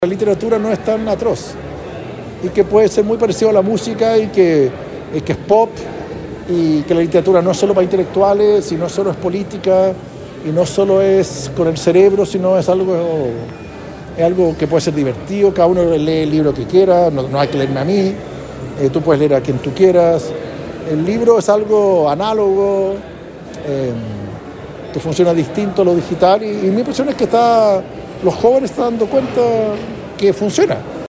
El escritor y cineasta participó en un conversatorio abierto a la comunidad en la Biblioteca Central